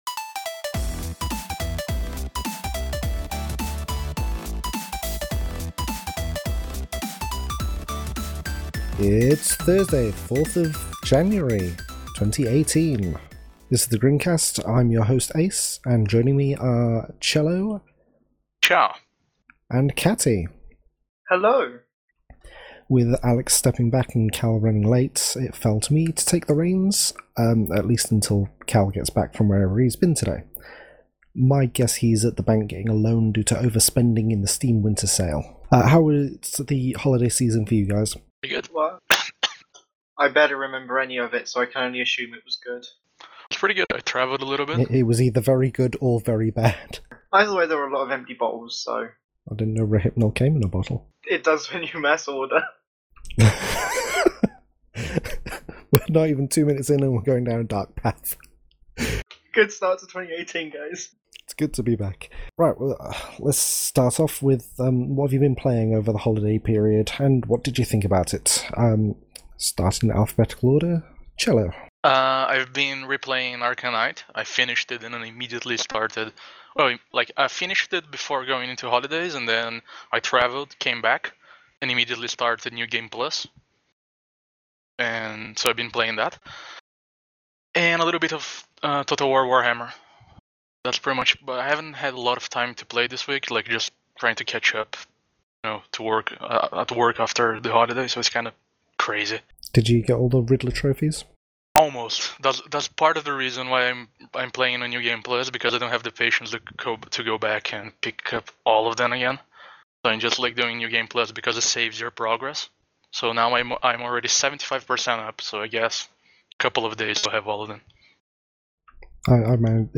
It was just a three-person team this episode, but we managed to make it work.